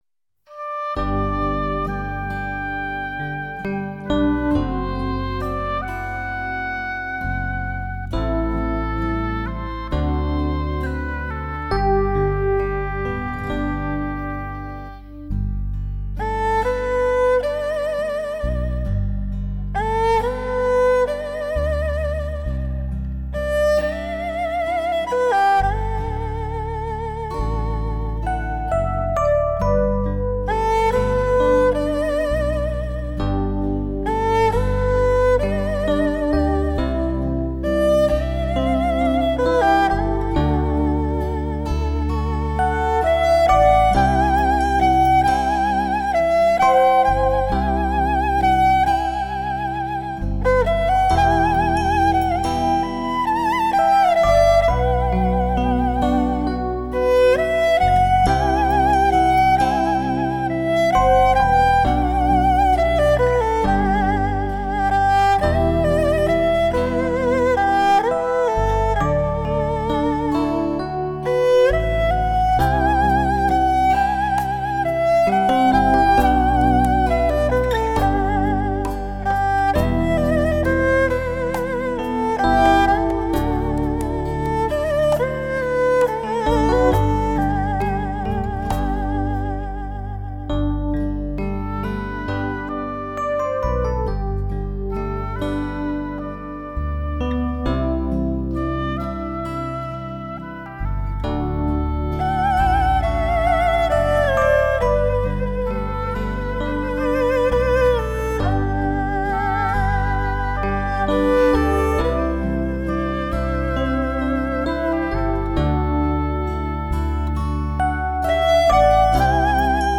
用中国的传统乐器-----二胡来演奏最经典的西洋歌曲或者是流行音乐，
运弓力道舒缓婉转，更在每一个音乐字句中展现她毫不修饰的情感世界。